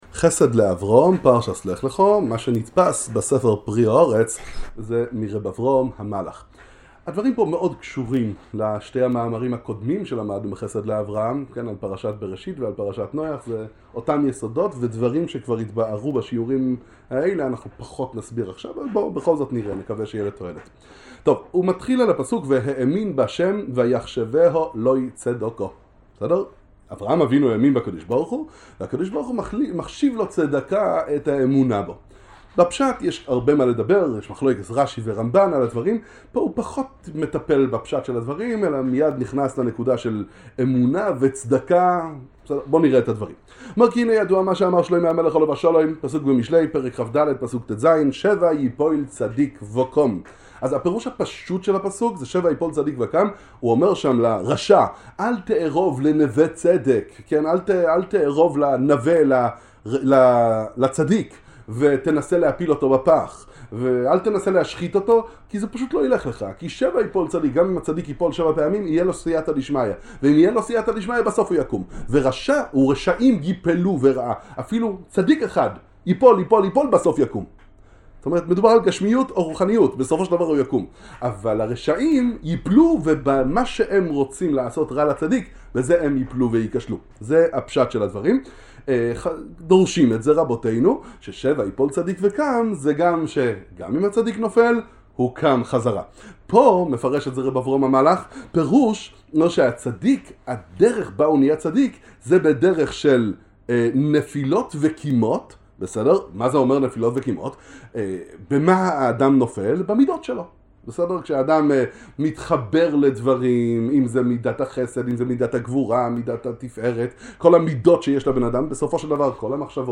שיעור עמוק ביסודות החסידות